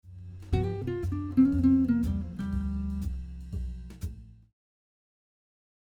Lick 4 imposes a G minor blues scale over the dominant 7 chord.
minor blues scale example